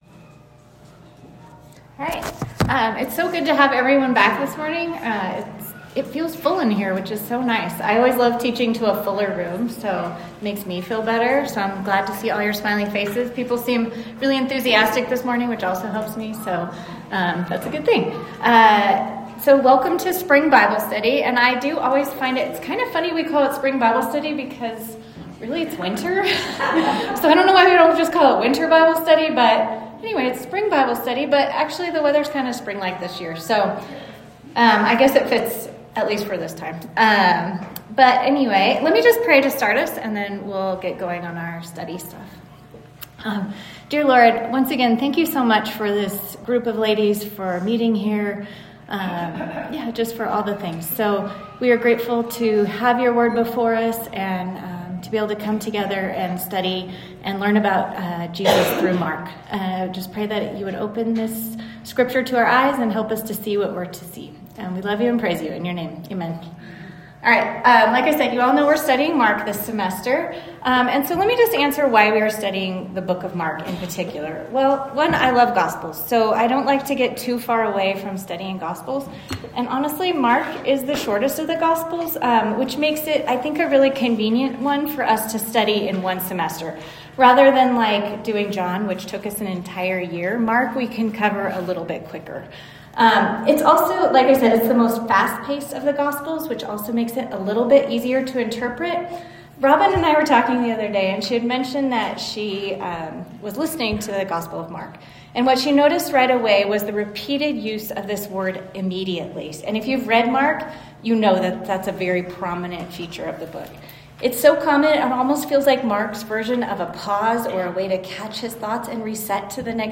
Women's Bible Study